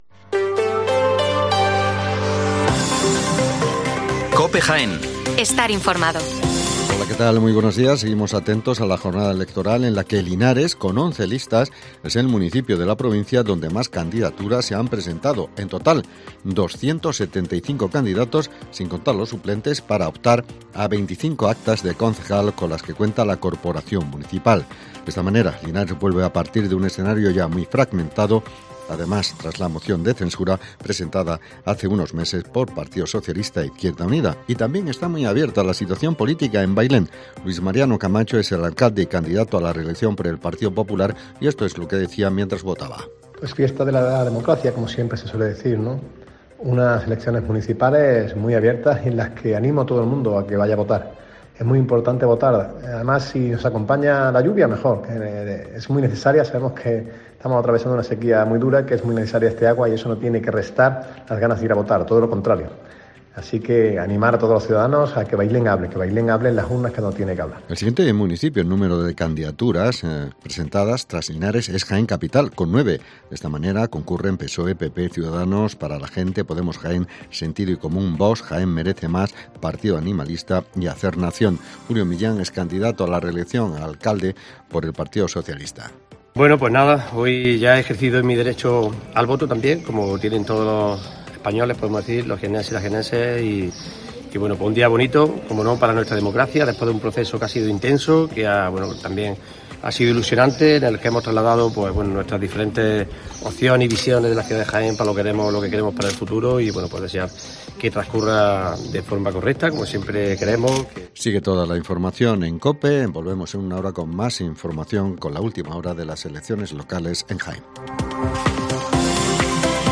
Especial Elecciones Municipales en Jaén. El informativo de las 11:05 horas